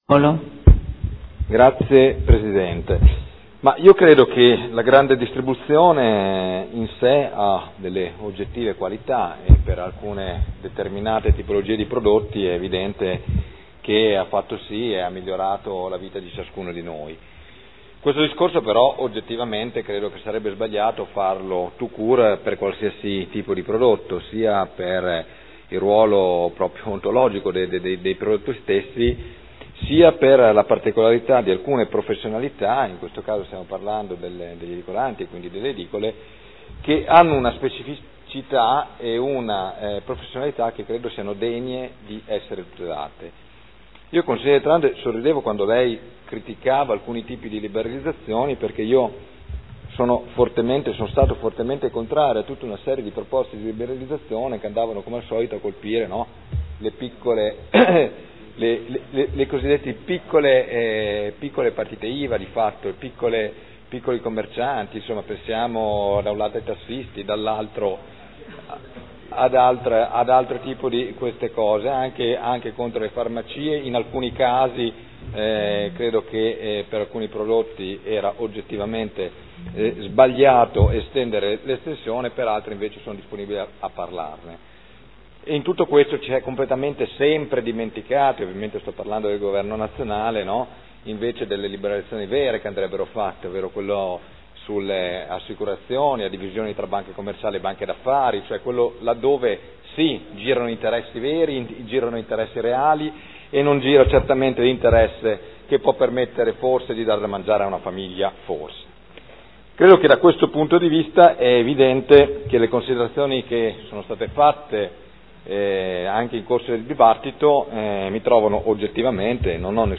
Seduta del 24/06/2013. Dibattito.